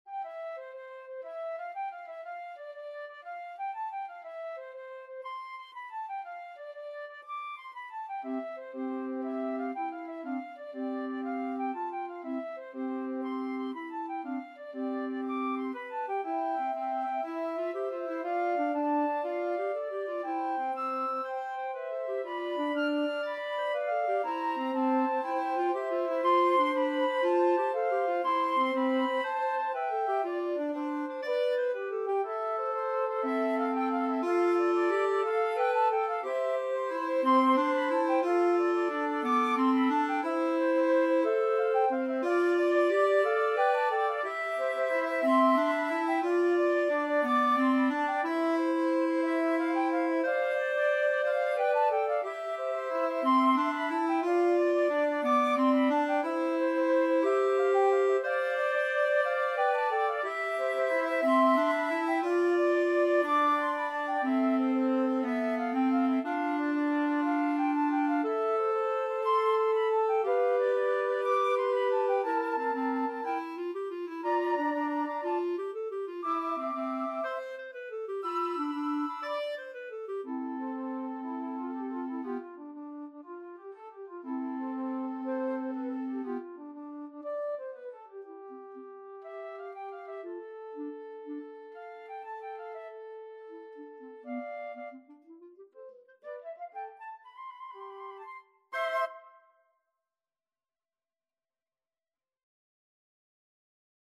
FluteAlto FluteClarinet
Allegro moderato . = 120 (View more music marked Allegro)
6/8 (View more 6/8 Music)
Classical (View more Classical Woodwind Trio Music)